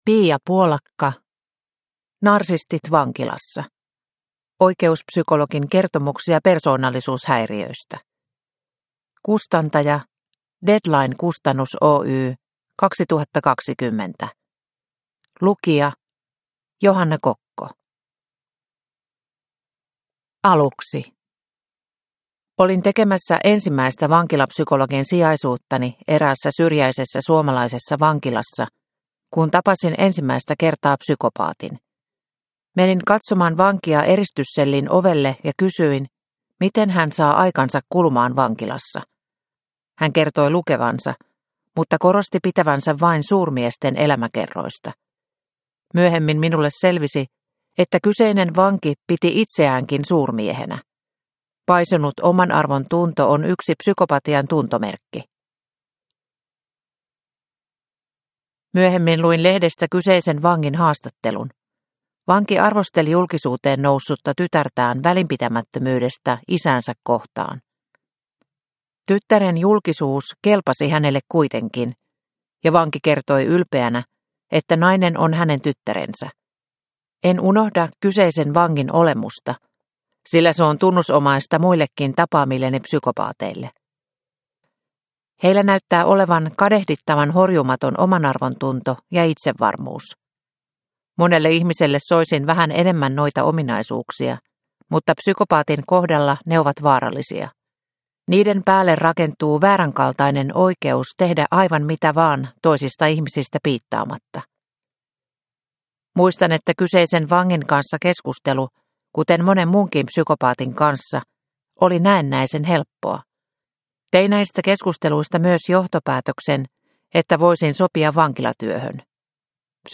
Narsistit vankilassa – Ljudbok – Laddas ner